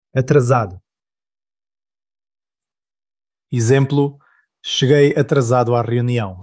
Pronunciación de la palabra